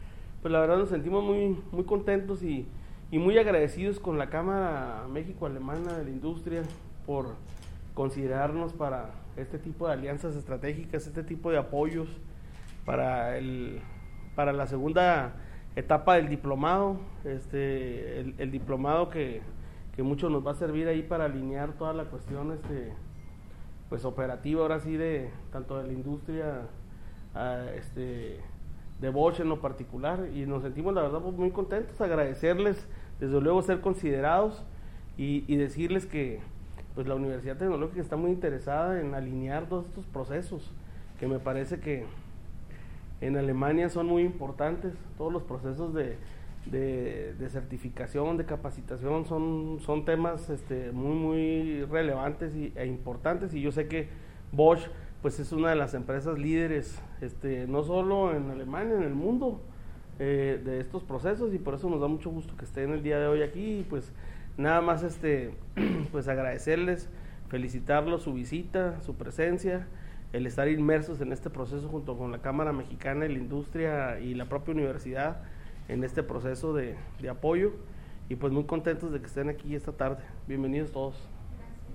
audio_rector_de_la_utcj_carlos_ernesto_ortiz_villegas.mp3